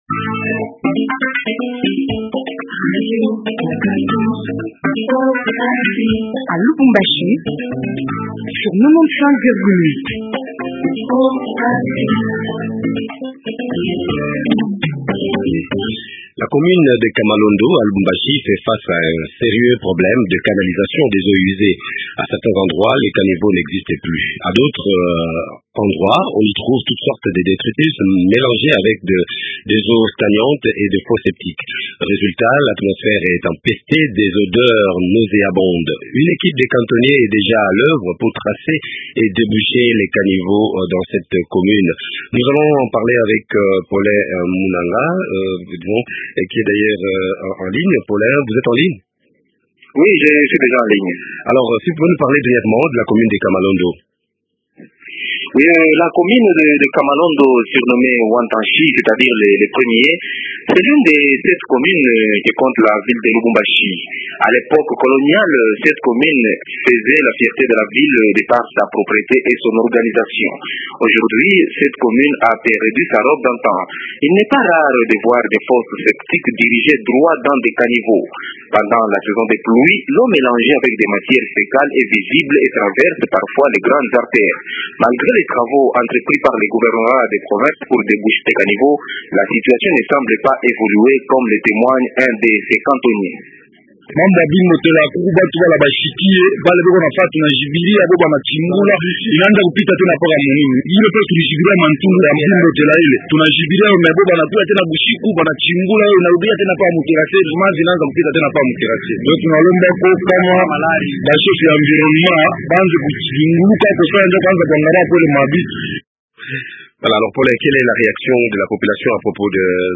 RENE Mukalay Lombe, bourgmestre de la commune de Kamalondo.